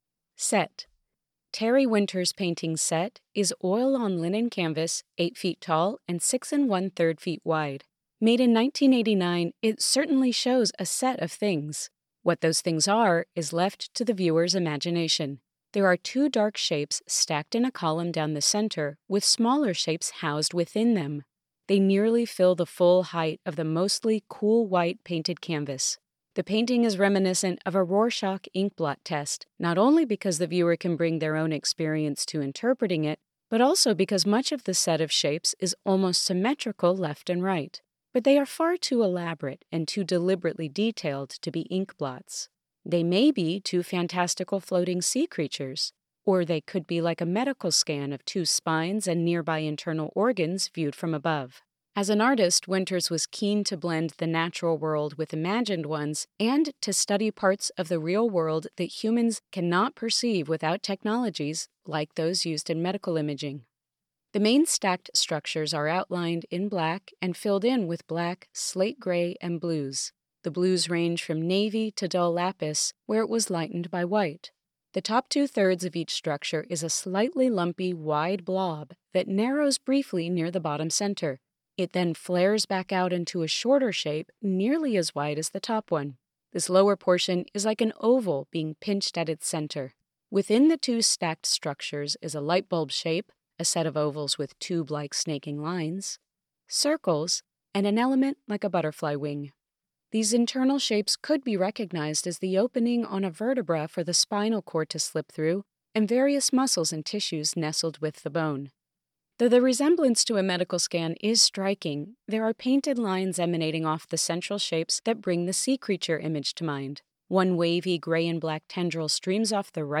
Audio Description (03:20)